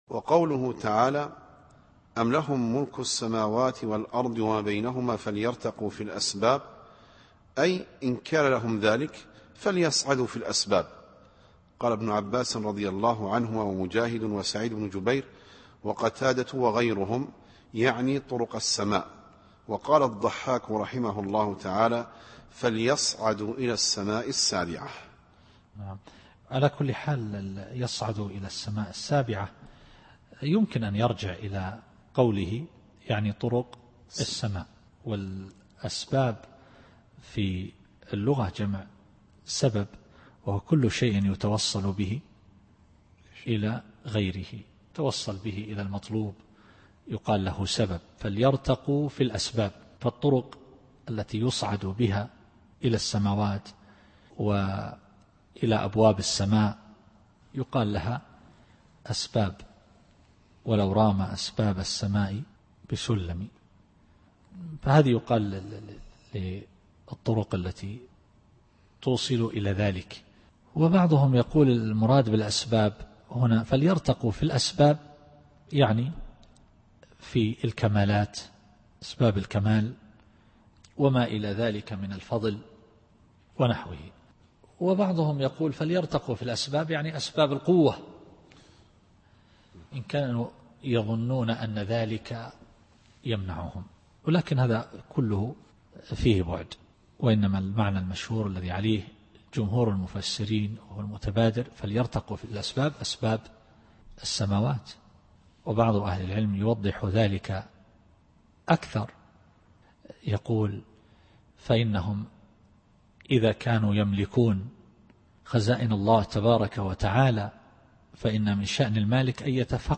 التفسير الصوتي [ص / 10]